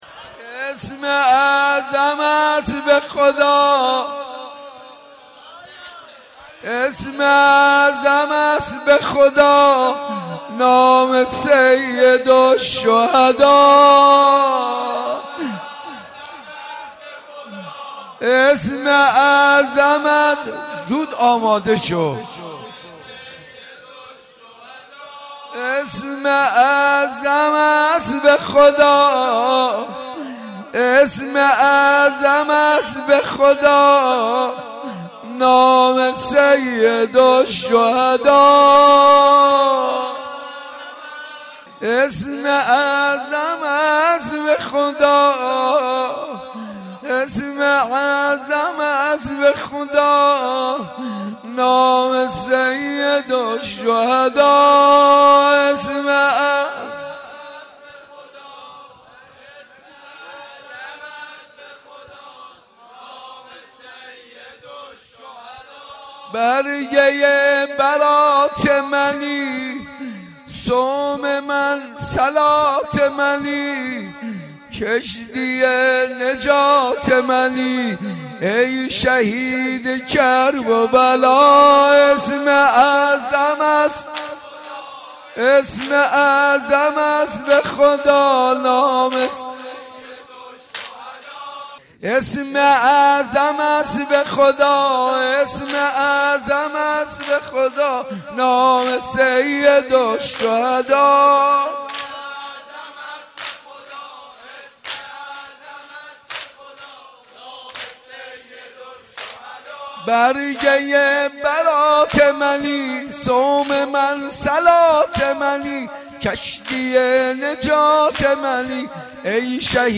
شب بیست و یکم محرم الحرام 1394
اسم اعظم است به خدا نام سیدالشهدا | زمینه | حضرت امام حسین علیه السلام